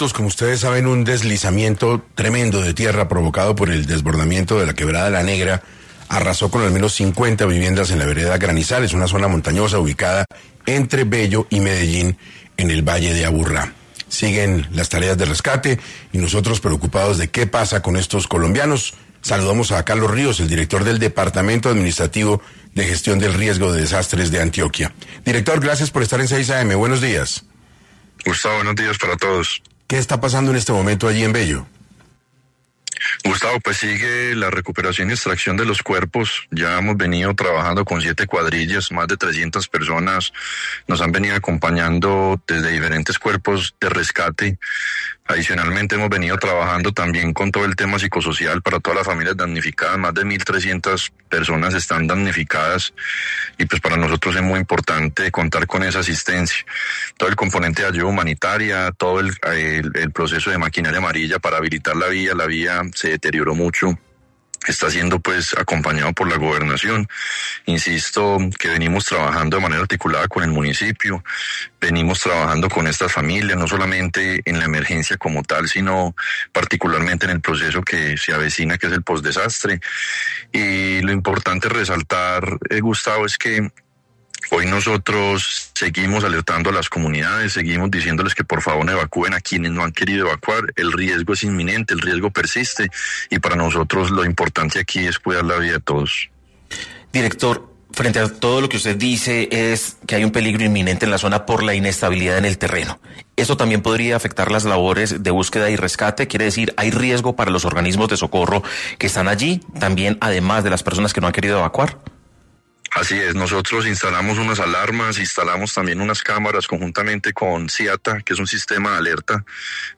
En ese orden de ideas, Carlos Ríos, director del Departamento Administrativo de Gestión del Riesgo de Desastres de Antioquia (Dagran), pasó por los micrófonos de 6AM para ampliar la información sobre los riesgos que existen en esta zona de Antioquia.